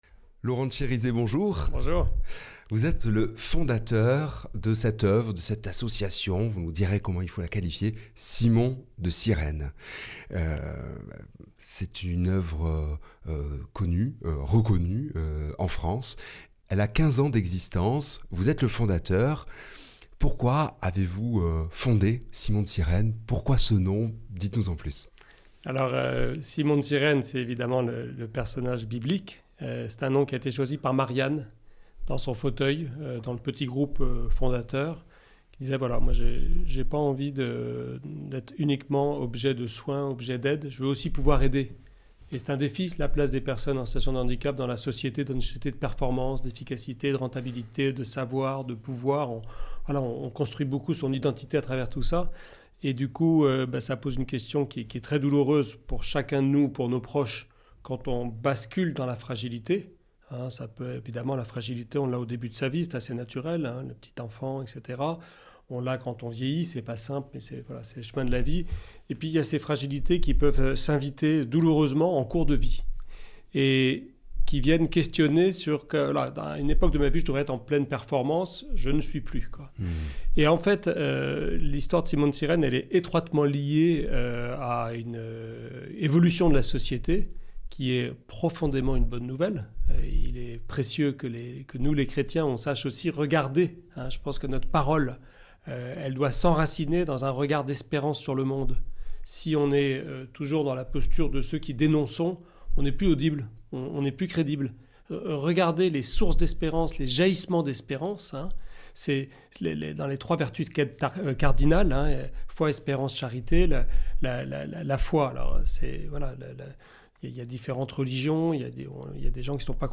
Accueil \ Emissions \ Information \ Régionale \ Le grand entretien \ Partager peut tout changer !